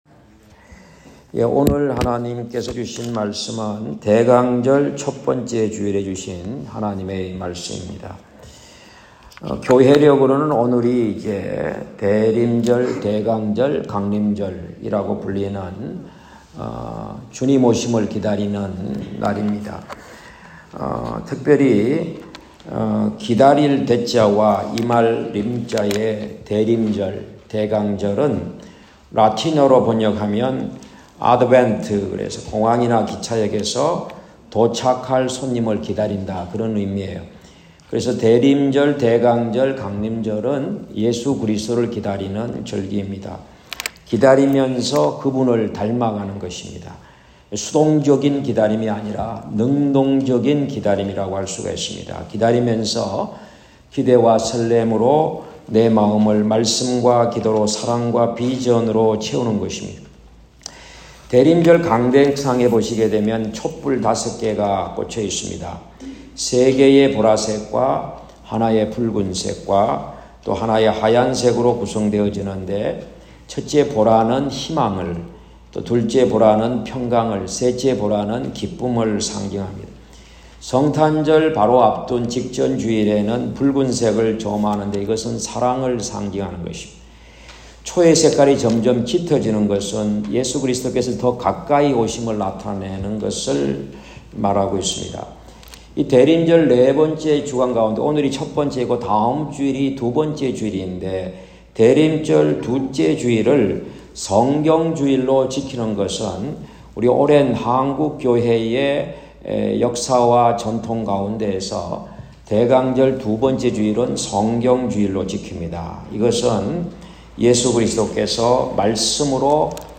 2024년 12월 1일 대강절(1) 주일설교(오전 11시, 2부)